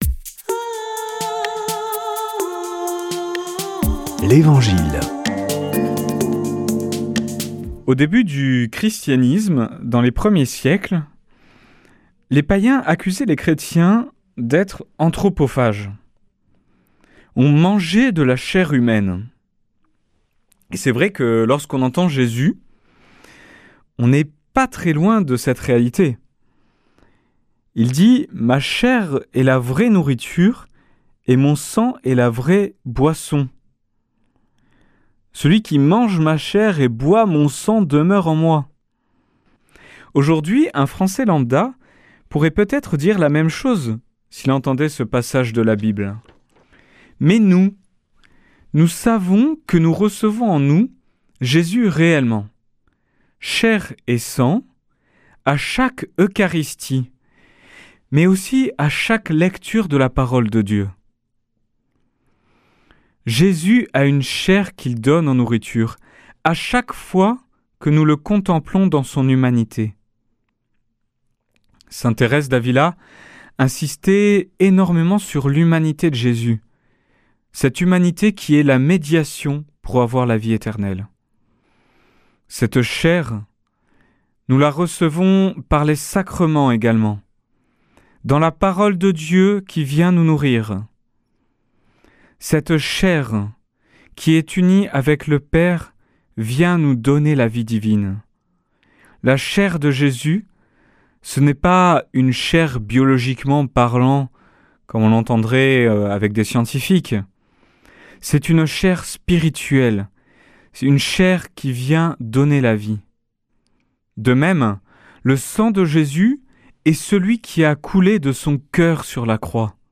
Des prêtres de la région